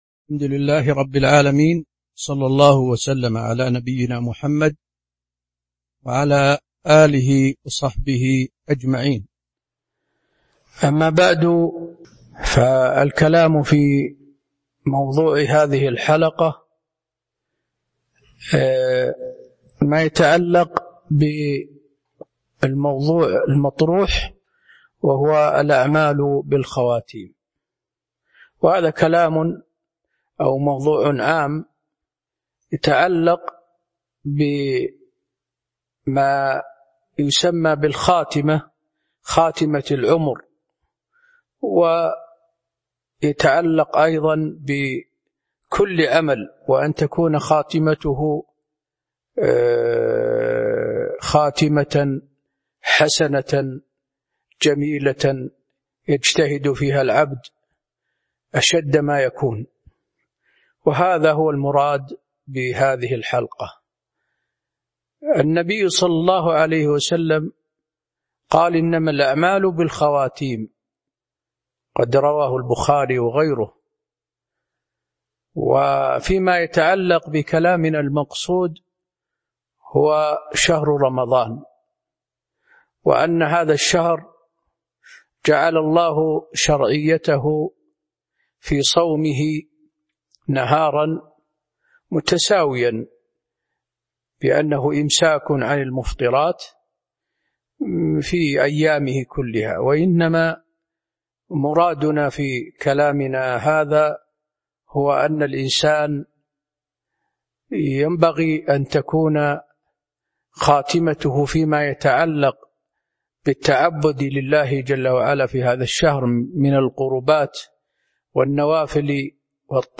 تاريخ النشر ٢٣ رمضان ١٤٤٢ هـ المكان: المسجد النبوي الشيخ: فضيلة الشيخ د. حسين بن عبدالعزيز آل الشيخ فضيلة الشيخ د. حسين بن عبدالعزيز آل الشيخ الأعمال بخواتيمها